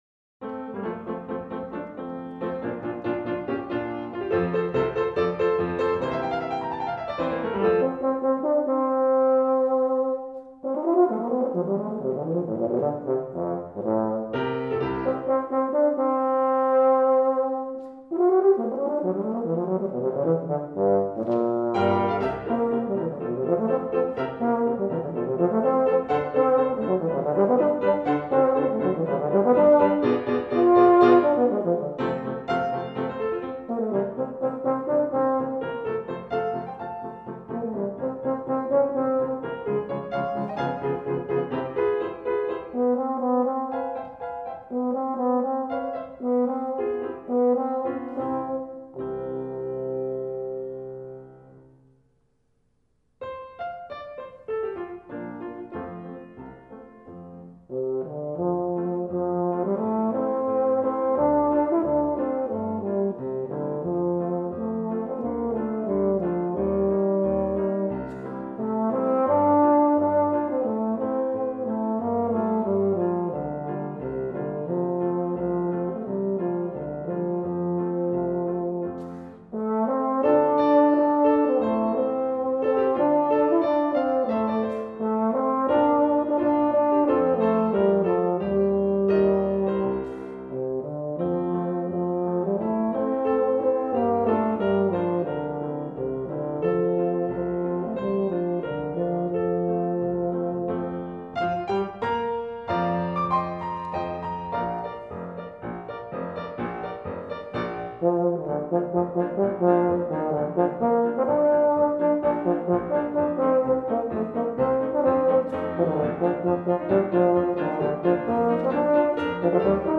For Euphonium Solo
with Piano